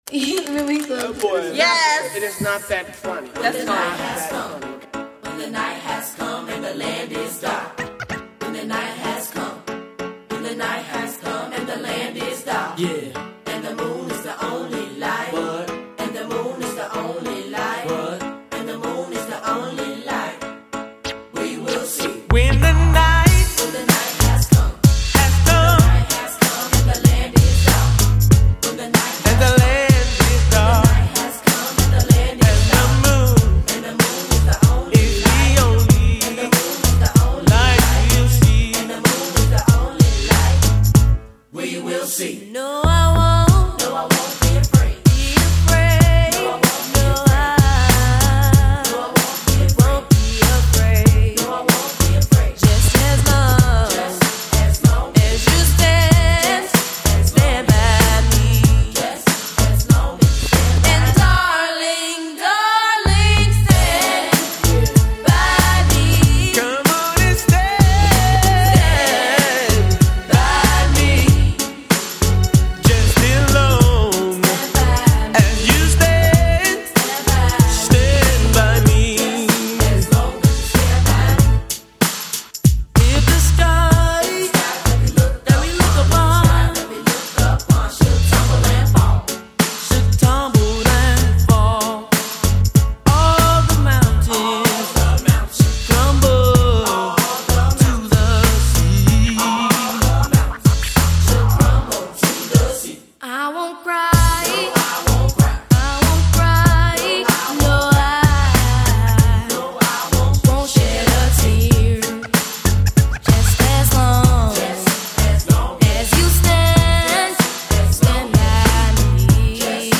【抒情慢摇】